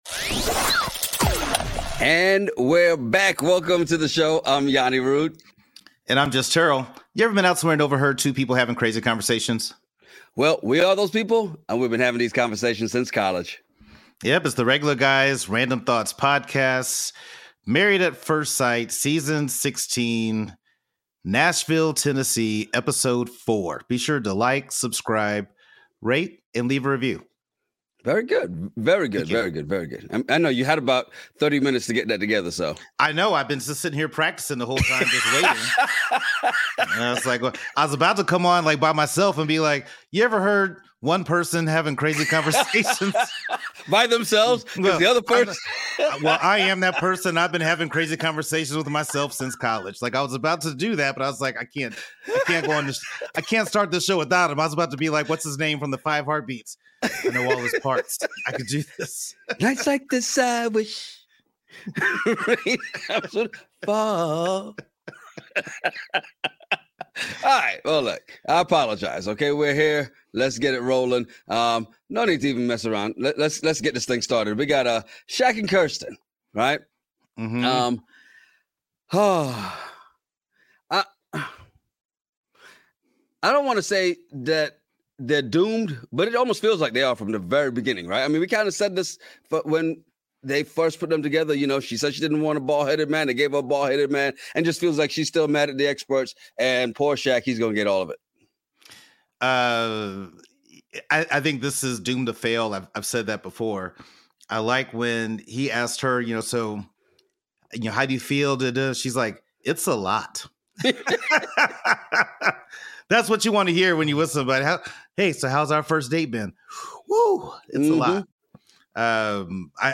Ever been somewhere and overheard two guys having a crazy conversation over random topics? Well we are those guys and we have been having these conversations since college.